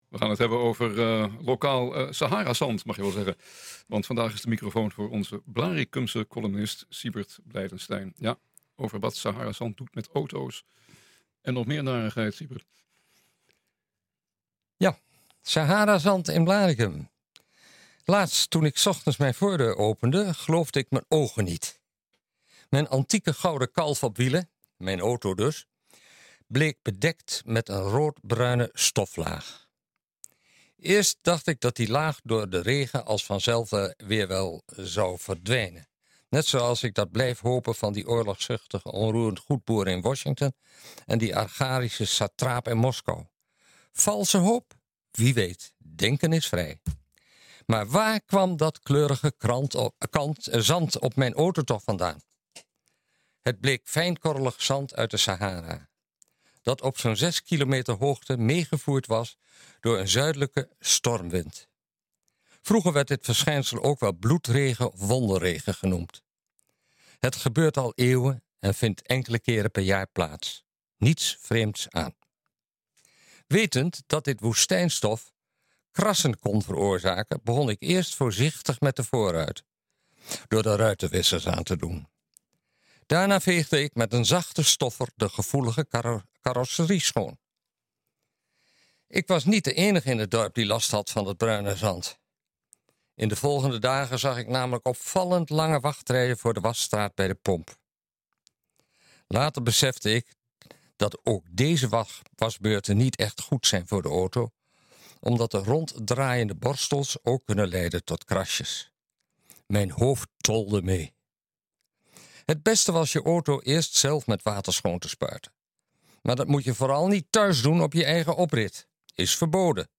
NH Gooi Zaterdag - Column